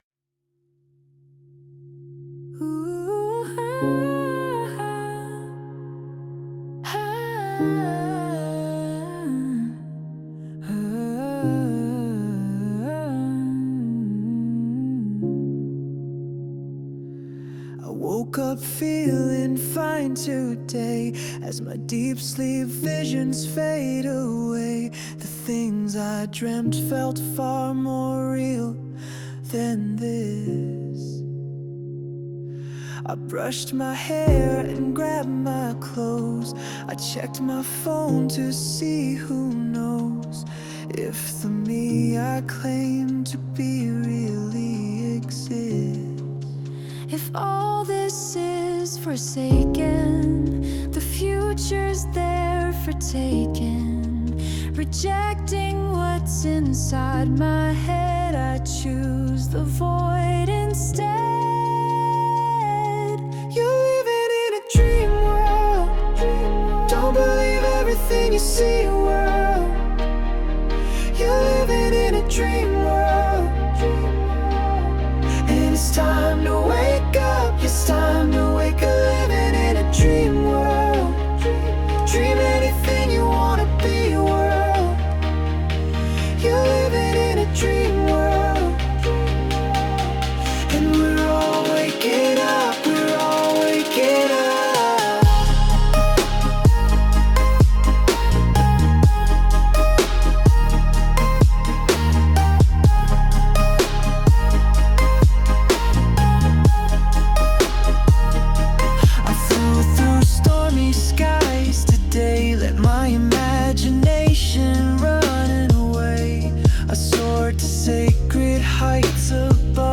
musical poetry
Most vocals are AI generated.
instrument performances by AI.
Tagged Under Gospel Musical Poetry New-Age